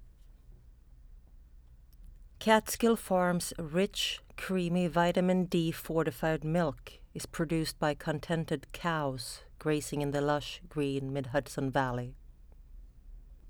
I put a towel and book under my mic and redid the forum test, which I’ve attached to this email. This raw sample is -55.4 dB.
I get -55.1dB, but yes, the evil, rumble, vibration sounds are gone.
I can clearly hear you moving around and breathing.